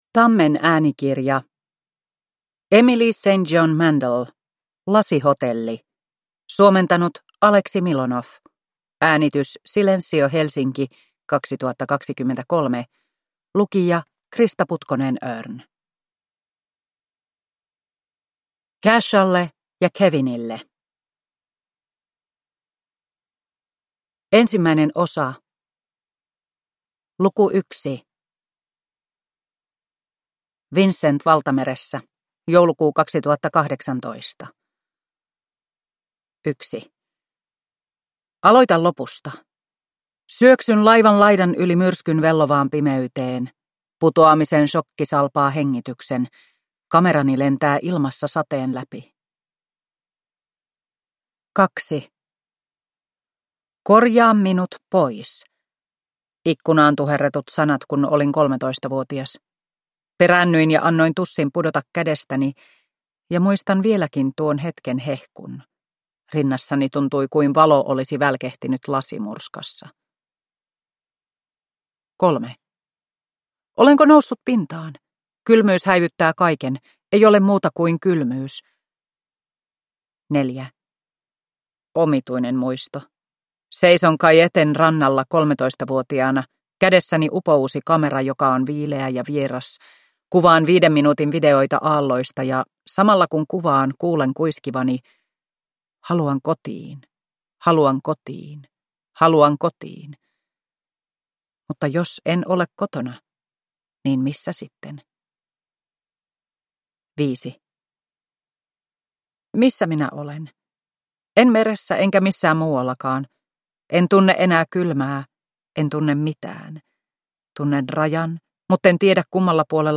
Lasihotelli – Ljudbok – Laddas ner